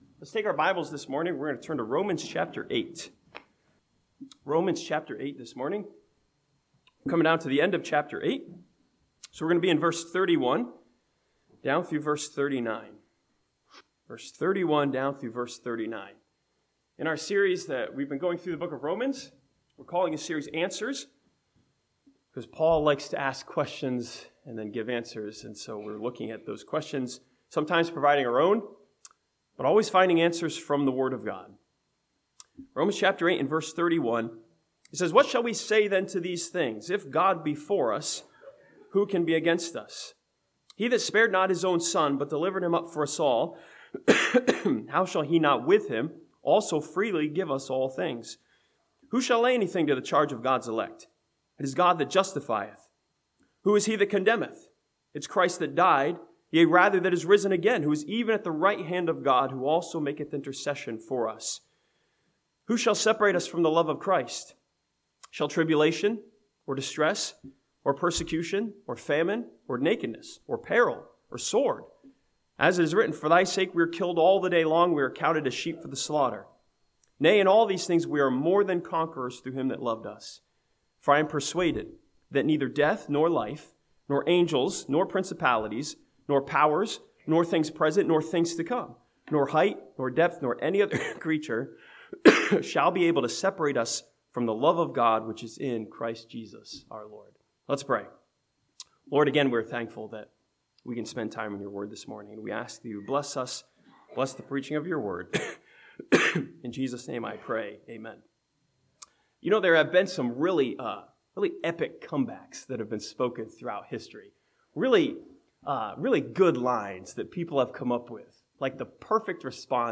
This sermon from Romans chapter 8 challenges us with a question of response: "what is our comeback?"